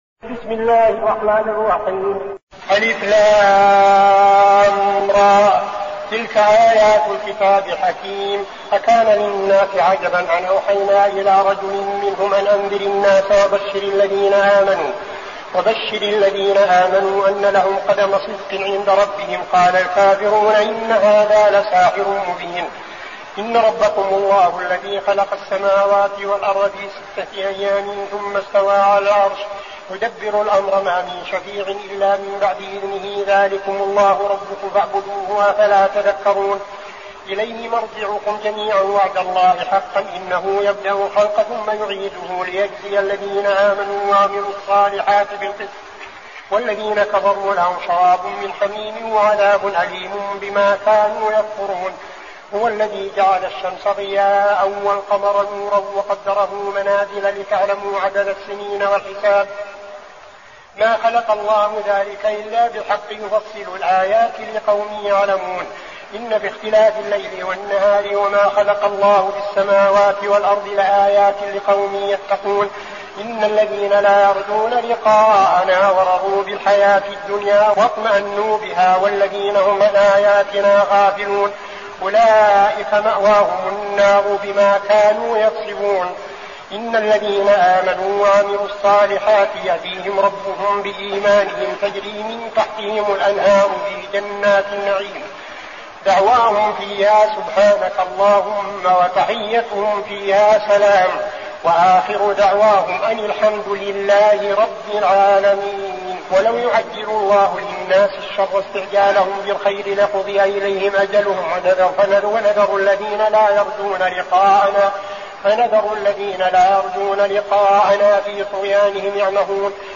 المكان: المسجد النبوي الشيخ: فضيلة الشيخ عبدالعزيز بن صالح فضيلة الشيخ عبدالعزيز بن صالح يونس